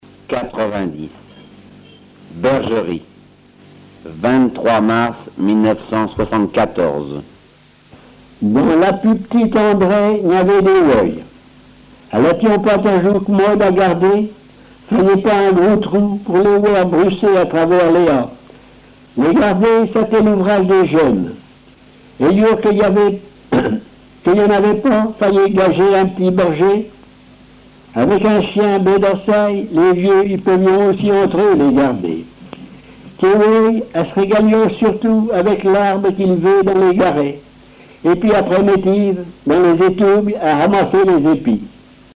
Genre récit
Récits en patois